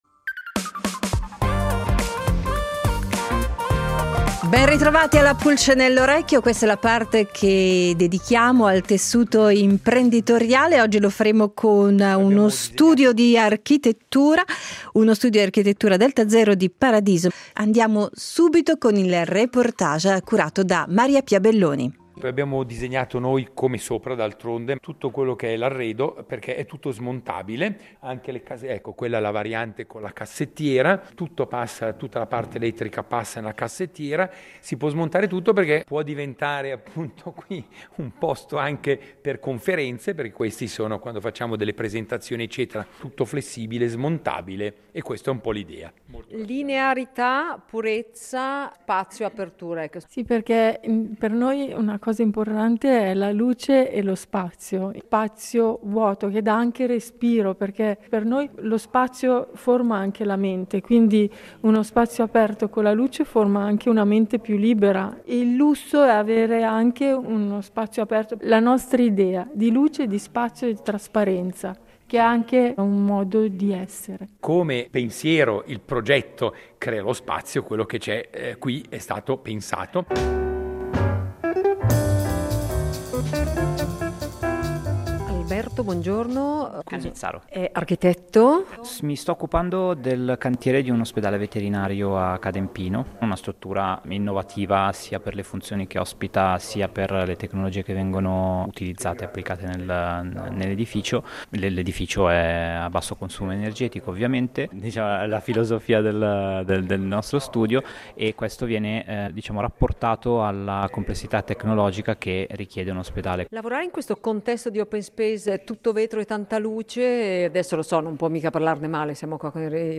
Dopo il reportage realizzato nella loro sede, in studio